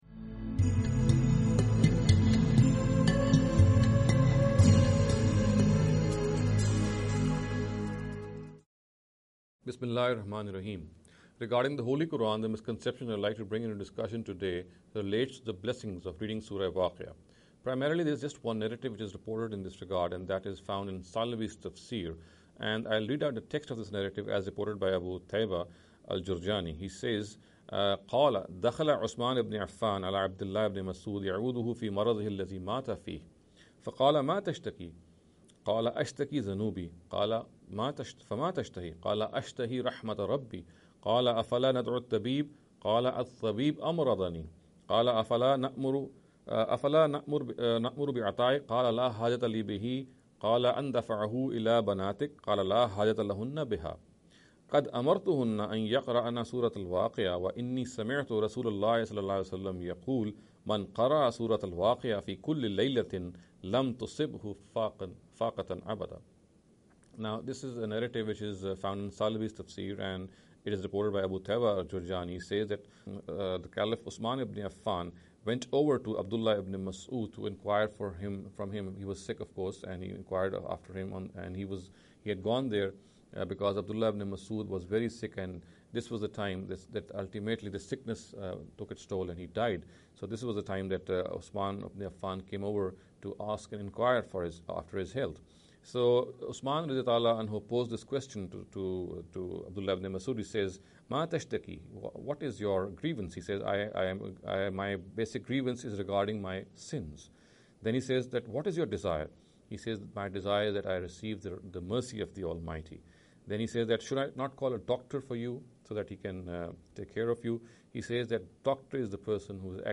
This lecture series will deal with some misconception regarding the Holy Quran.